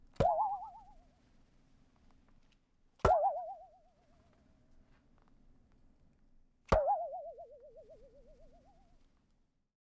Sci-fi Bounce
bladerunner boing bounce delay fiction freaky frog funny sound effect free sound royalty free Funny